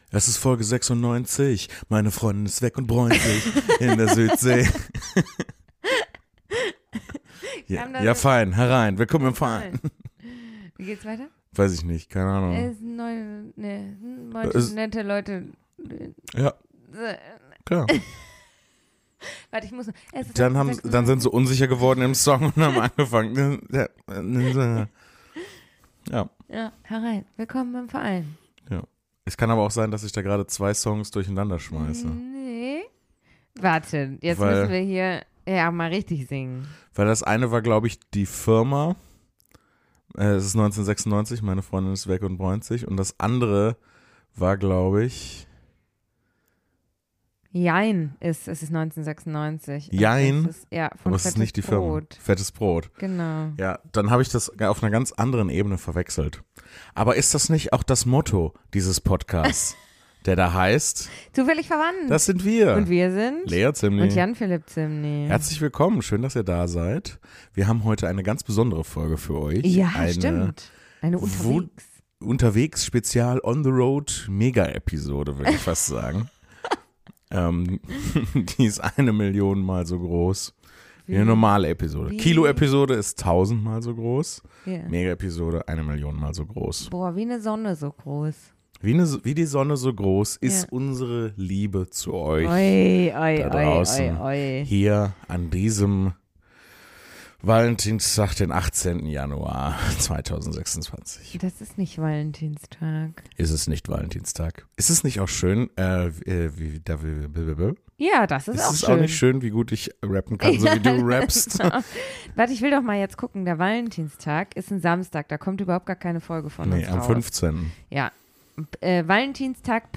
Sie bräunen sich im gemeinsamen unterwegs sein und nehmen deshalb auch die Folge aus dem Hotel heraus auf. Es geht um Aufgeregt sein, wie viele Leute welchen unterschied dabei machen und um Starallüren